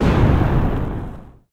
Slightly better titan shoot SFX
titanExplosion.ogg